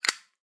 zippo_close_01.wav